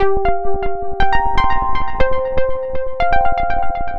Pro Bass Lead.wav